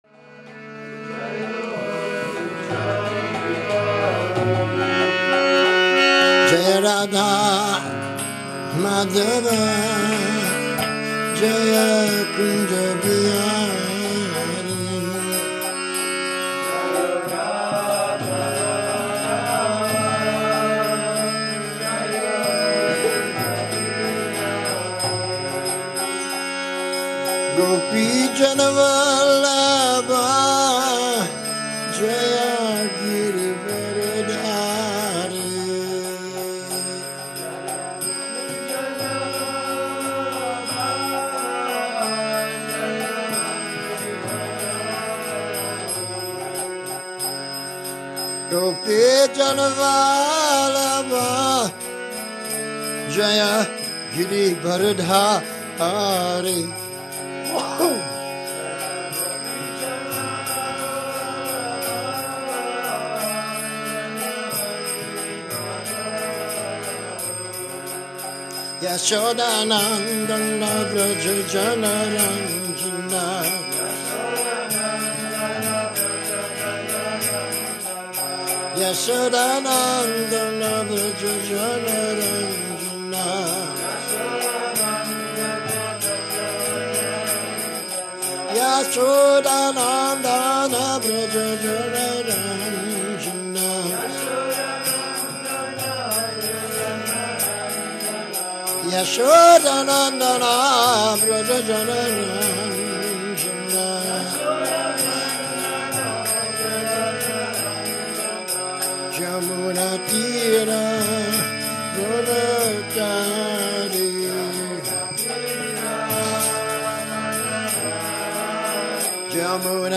Přednáška SB-1.2.26 – Šrí Šrí Nitái Navadvípačandra mandir